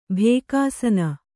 ♪ bhēkāsana